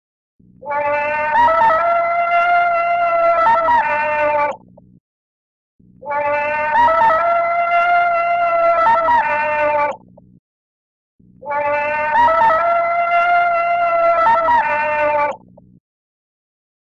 دانلود آهنگ تارزان 1 از افکت صوتی انسان و موجودات زنده
جلوه های صوتی
دانلود صدای تارزان 1 از ساعد نیوز با لینک مستقیم و کیفیت بالا